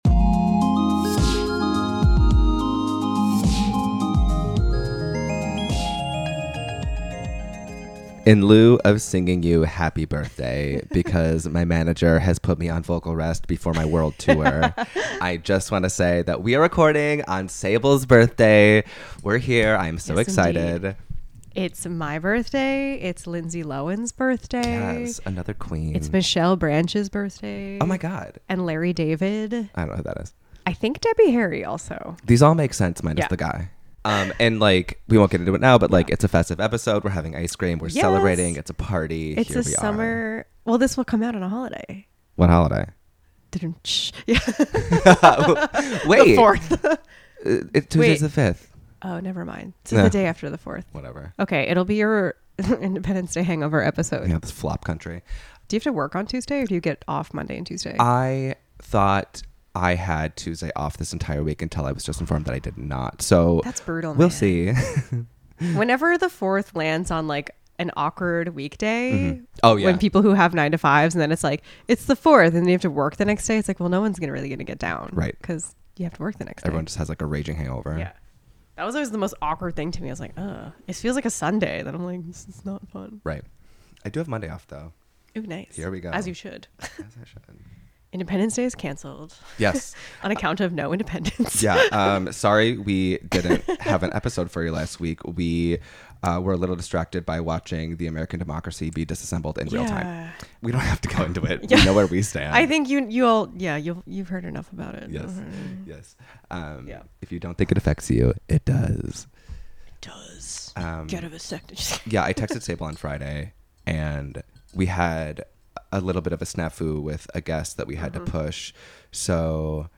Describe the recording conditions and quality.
We tried to mute as much of the mouth sounds as possible in this experiential episode but we had to give you a live taste-smell sesh for the occasion.